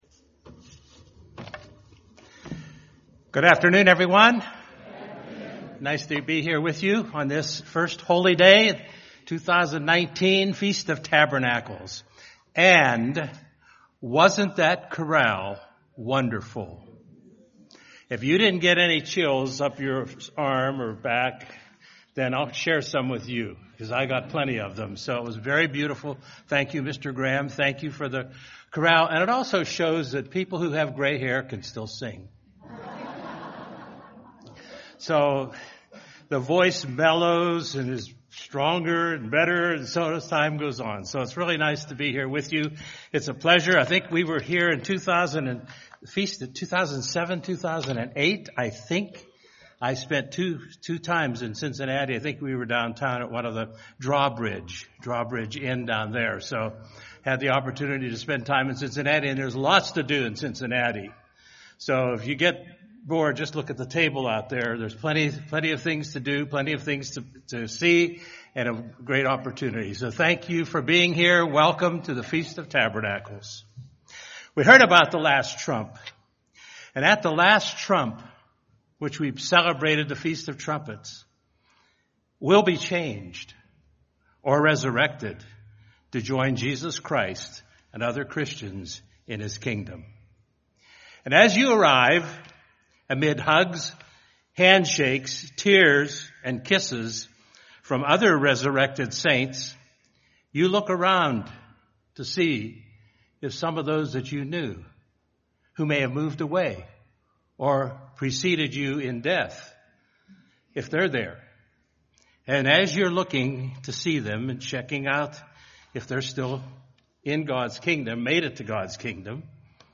This sermon explains what it will take to turn the world that’s been wrecked and decimated into the world we will inherit as the Kingdom of God.
This sermon was given at the Cincinnati, Ohio 2019 Feast site.